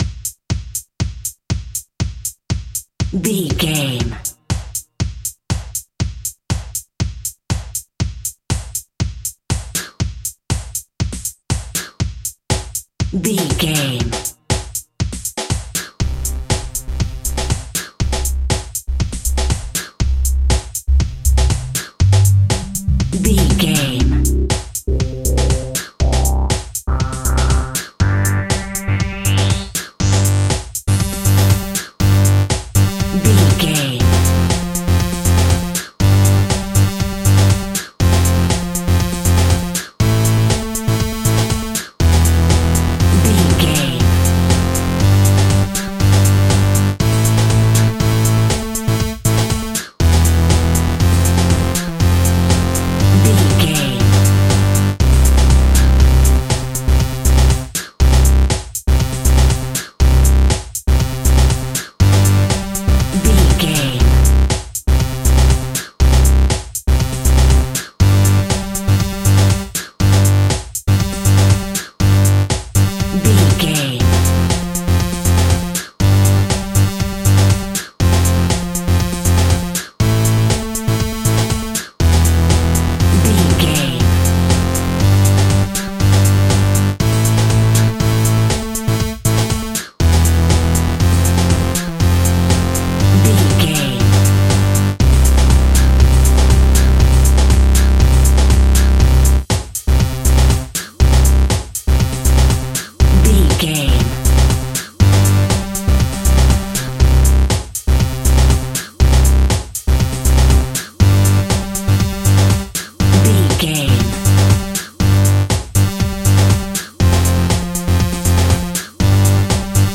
Fast paced
Ionian/Major
energetic
driving
futuristic
drum machine
synthesiser
electro house
synth pop